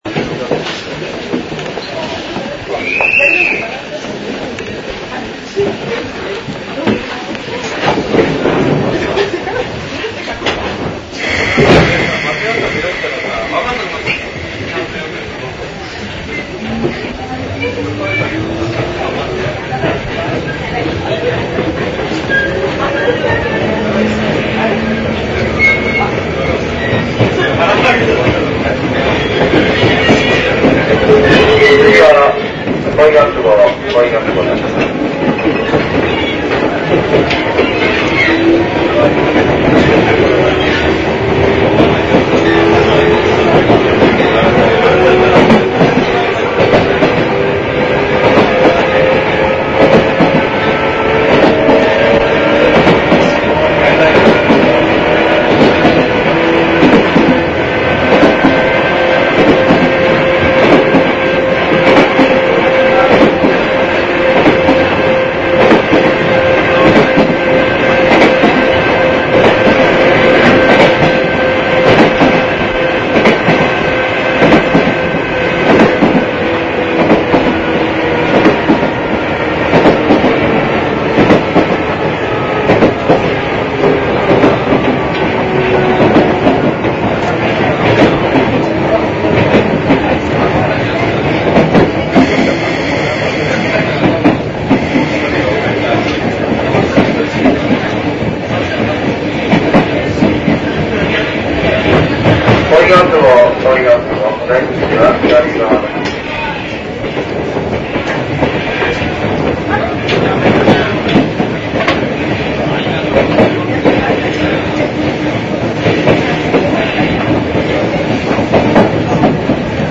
元テープは古く、また録音技術も悪いため、音質は悪いかも知れません。
H　モハ７５４　（８２５ＫＢ　２．２０秒）　国分寺線　鷹の台・恋ヶ窪間走行音　冷改前　ロングレール化前　全区間紹介